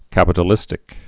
(kăpĭ-tl-ĭstĭk)